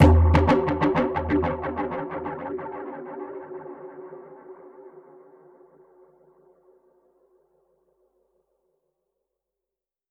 DPFX_PercHit_B_95-06.wav